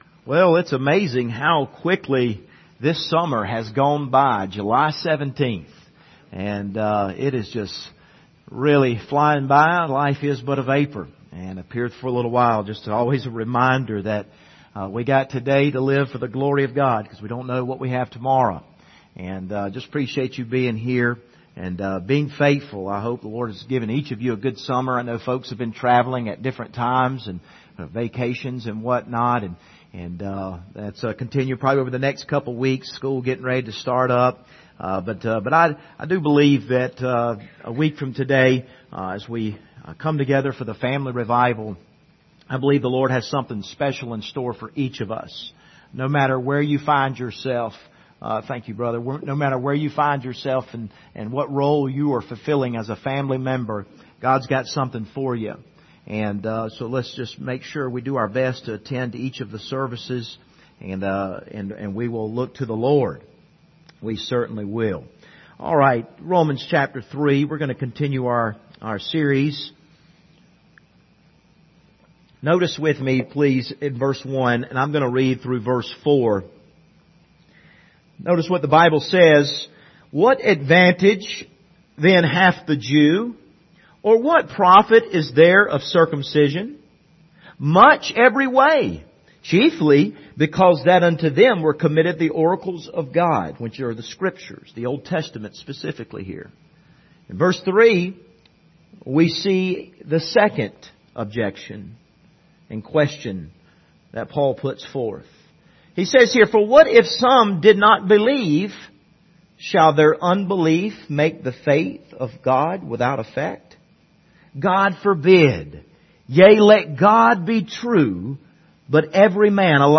The Just Shall Live By Faith Passage: Romans 3:1-4 Service Type: Sunday Morning « Keep Walking Till I Tell You to Shout What Do We Do When God Speaks?